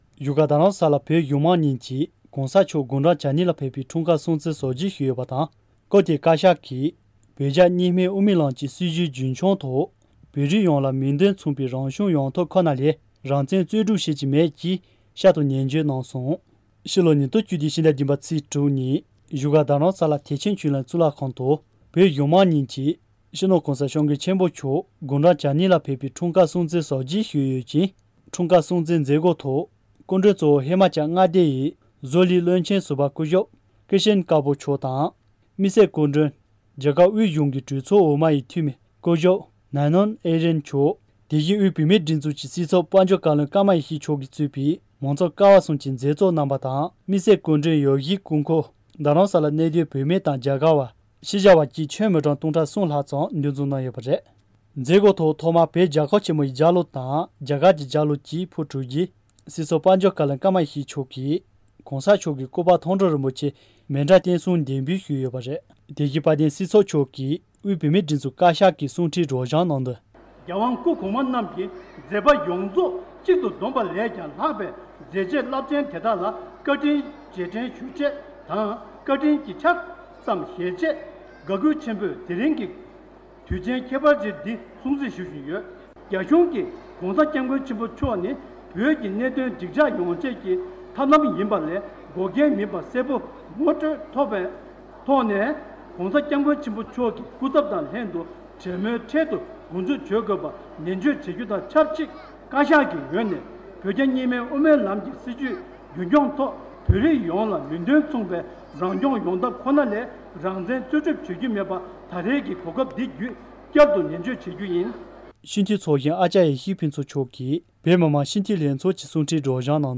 ༧གོང་ས་མཆོག་དགུང་གྲངས་༨༢ ལ་ཕེབས་པའི་སྐུའི་འཁྲུངས་སྐར། བཞུགས་སྒར་རྡ་རམ་ས་ལ། སྒྲ་ལྡན་གསར་འགྱུར།